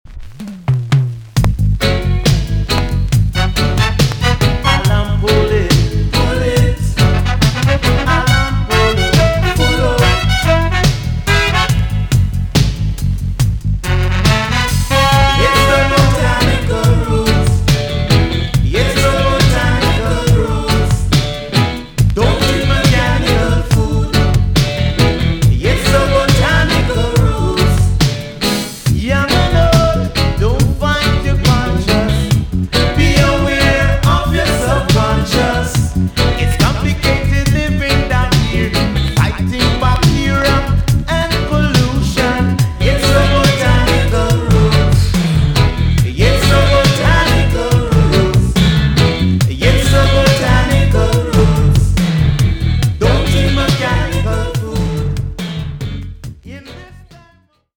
TOP >80'S 90'S DANCEHALL
EX-~VG+ 少し軽いチリノイズが入りますが良好です。